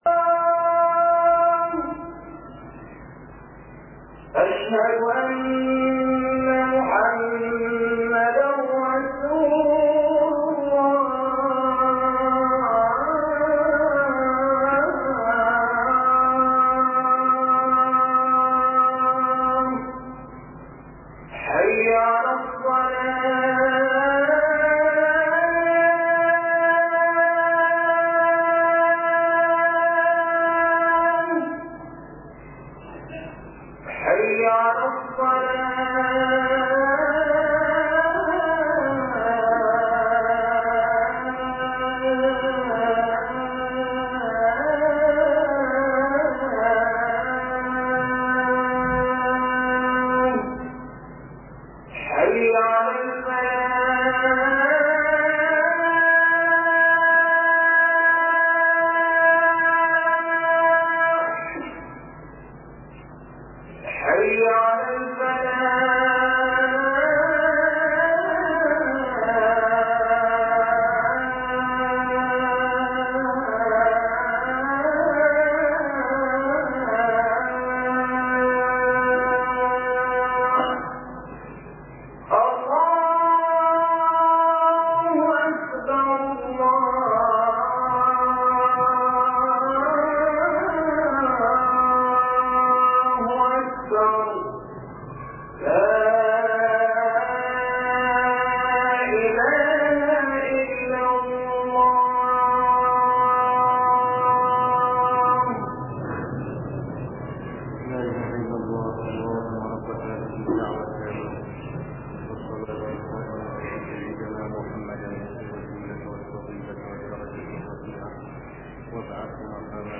Play and download the audio file '012 KHUDBAH SHARIF IBRAHIM.mp3' from the series 'KHUDBAH JUMA-A ' by SHEIKH SHARIF IBRAHIM SALEH CON.